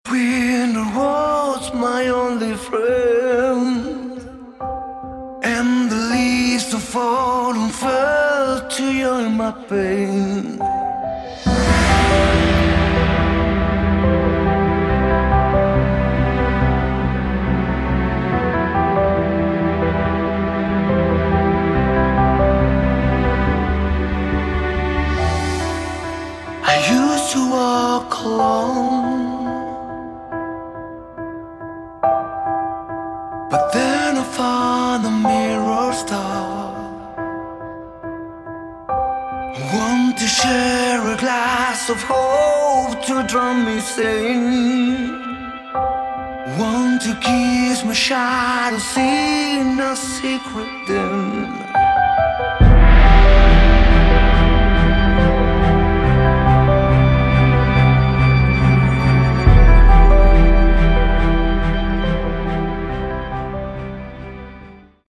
Category: Melodic Metal
Vocals
Guitar, vocals, keyboards
Bass, vocals
Drums